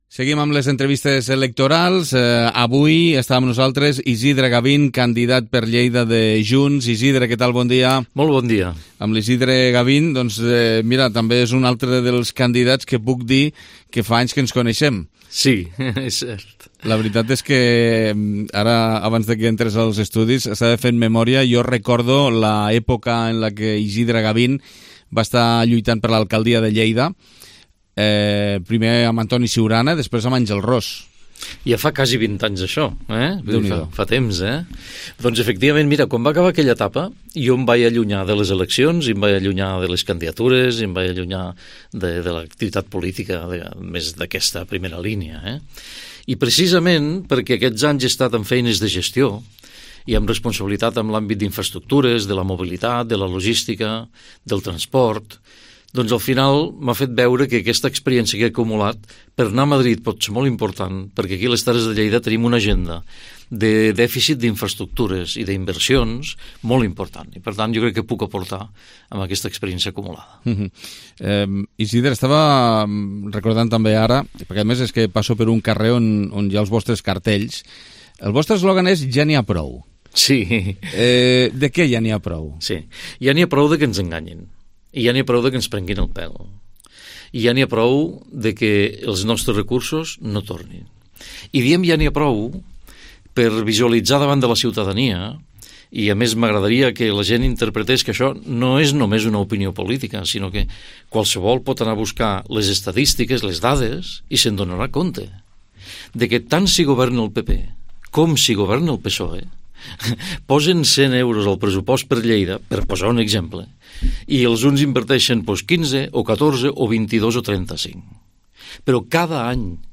Entrevista Campanya Electoral 23J2023 - Isidre Gavin - Junts